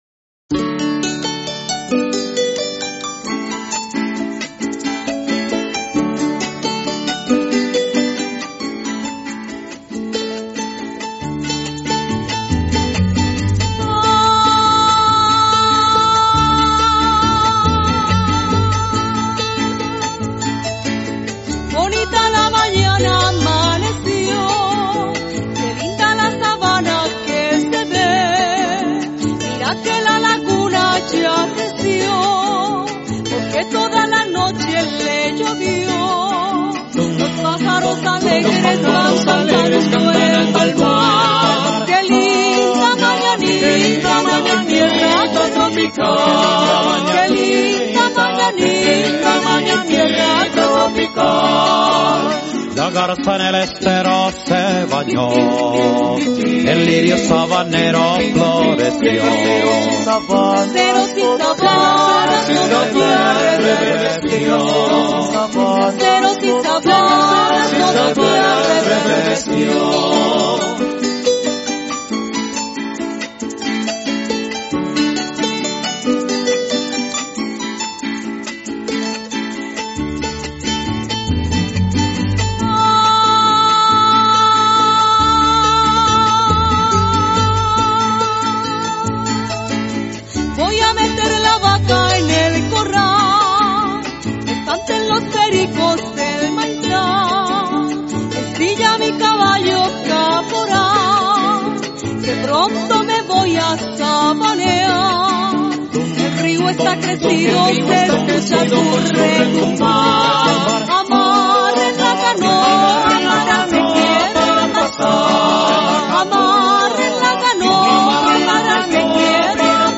Pasaje Llanero